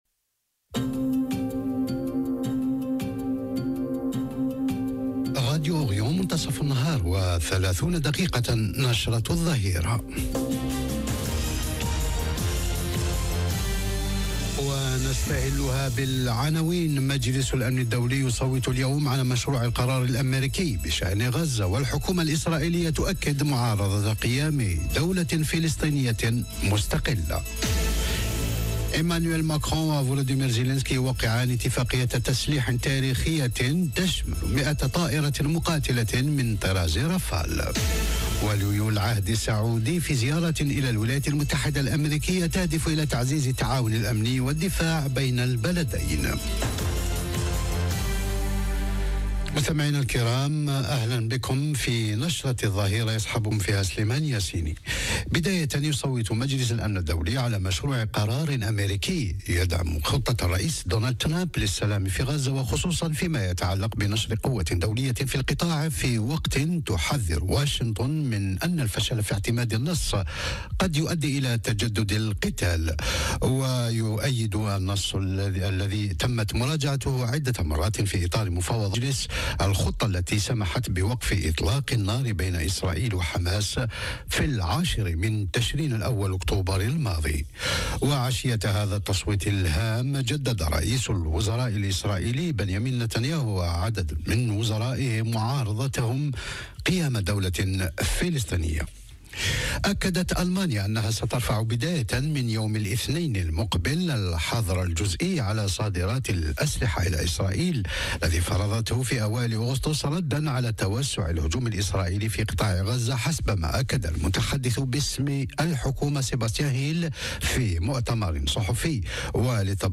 نشرة أخبار الظهيرة: مجلس الأمن الدولي يصوت اليوم على مشروع القرار الأميركي بشأن غزة والحكومة الإسرائيلية تؤكد معارضة قيام دولة فلسطينية مستقلة - Radio ORIENT، إذاعة الشرق من باريس